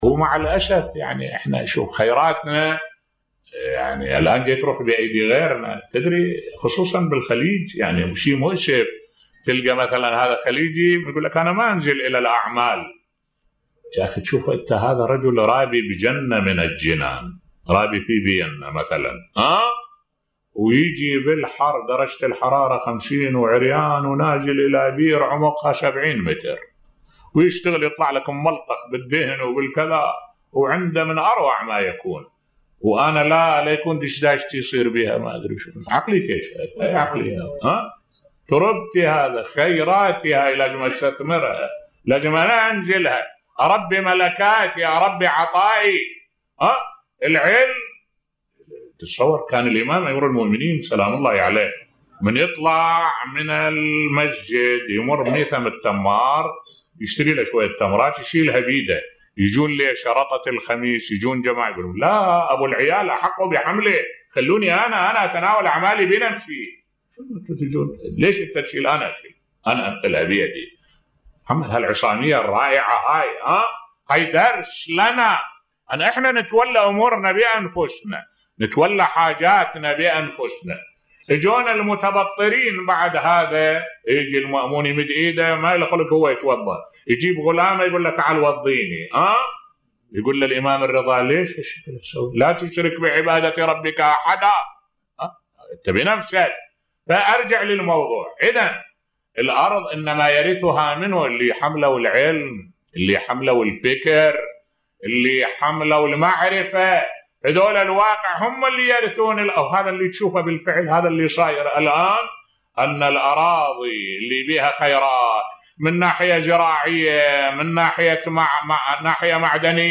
ملف صوتی الشيخ الوائلي ينتقد دول الخليج لتبديدهم أموال بلدانهم بصوت الشيخ الدكتور أحمد الوائلي